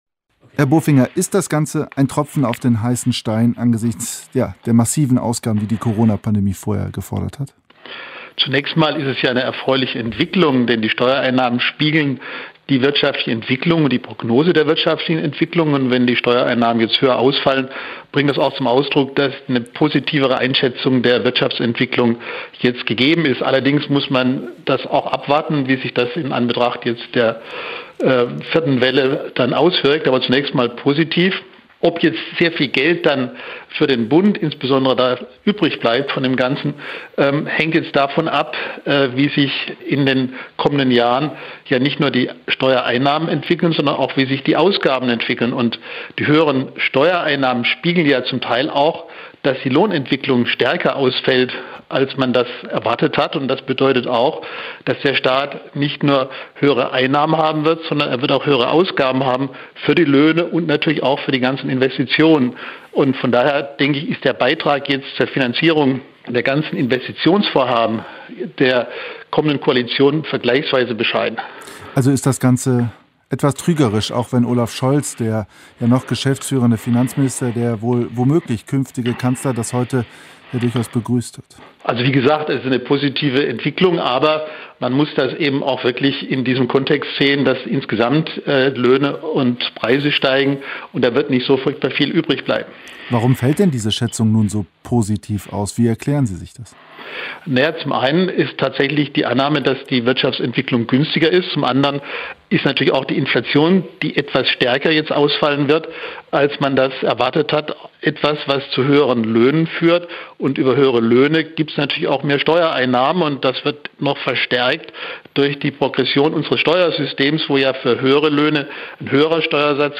Interview mit Peter Bofinger